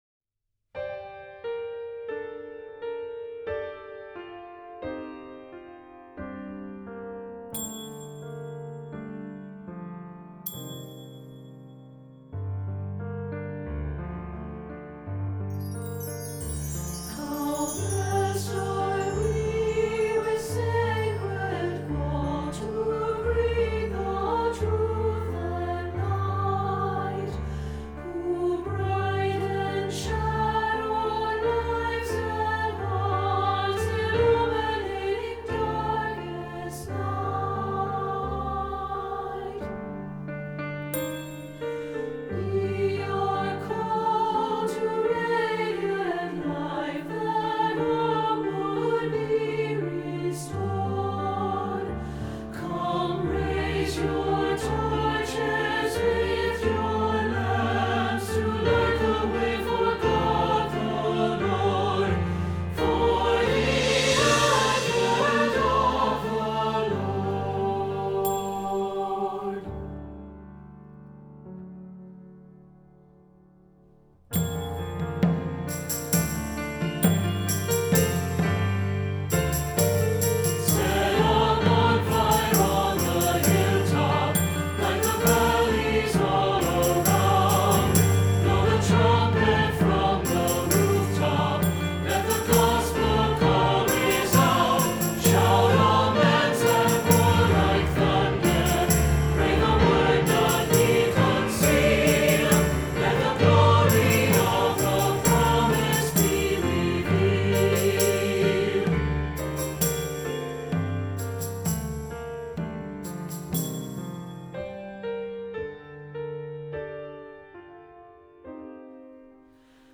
Choral Christmas/Hanukkah
2 Part Mix